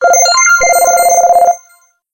Melody Bell.mp3